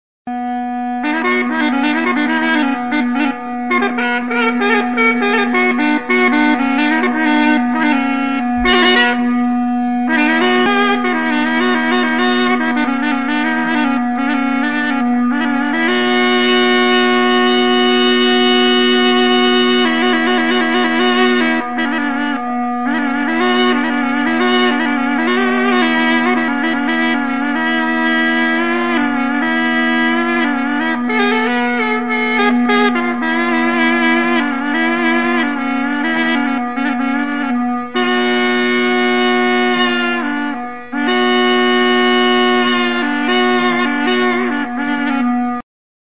ARGHUL; ARGUL | Soinuenea Herri Musikaren Txokoa
Klarinete bikoitza da.